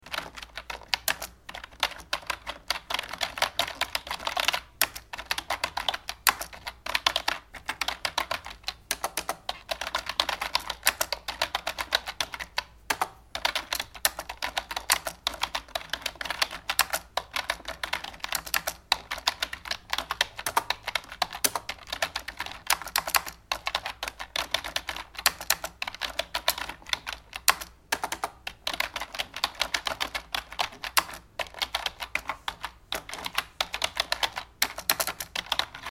جلوه های صوتی
دانلود صدای کیبورد 6 از ساعد نیوز با لینک مستقیم و کیفیت بالا
برچسب: دانلود آهنگ های افکت صوتی اشیاء دانلود آلبوم صدای کیبورد از افکت صوتی اشیاء